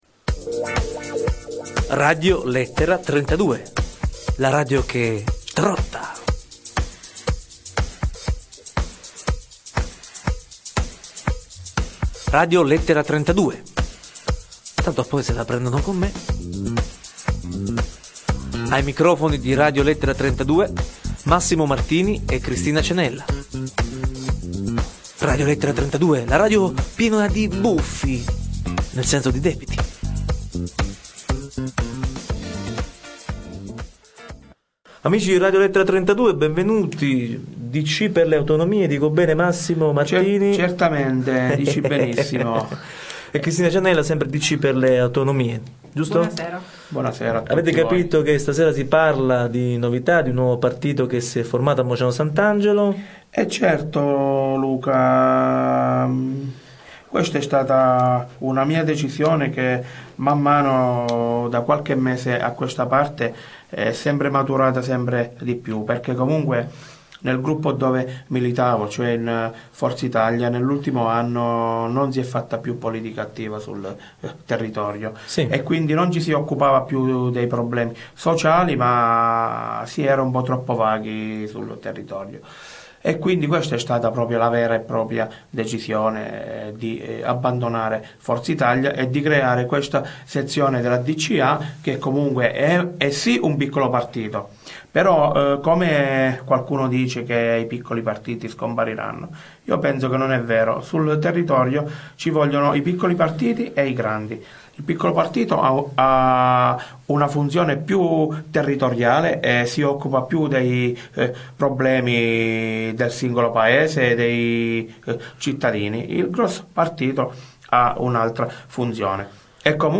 qui per l'intervista (podcast in mp3, 4,5 mega)